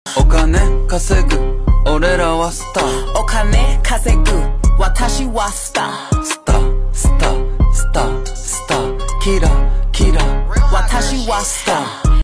Answer format: ***** **** Answer: morse code Question 4: What link is hidden in the audio after converting?